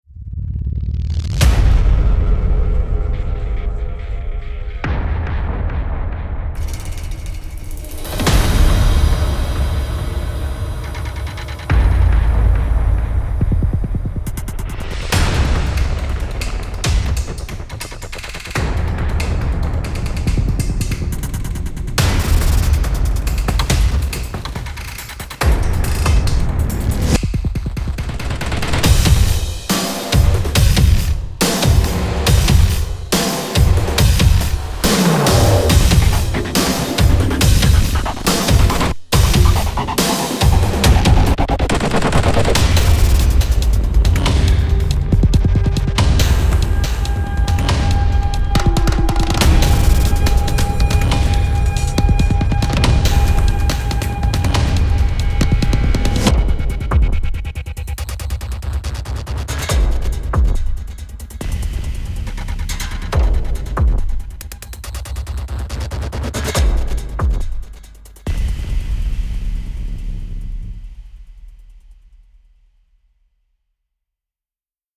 黑暗、侵略性、威胁性 - DAMAGE 是一款令人心颤的银幕式打击乐工具，它有着强大的张力以及史诗感。 它由 Heavyocity 制作而成，融合了交响打击乐、惊艳的采样声效，以及尖端的电子元素。
DAMAGE 提供了 30GB 风格剧烈化的工业之声以及交响鼓组，并将它们融入到密集电子原声之中。
Percussive Kits 打击乐套件包含 58 NKIs: 13 款史诗交响鼓, 10 款民族鼓, 13 款金属, 9 款混合 FX, 13 款毁坏套件
声音类别 史诗银幕, 史诗 Tech, 工业电子以及 Mangled Pop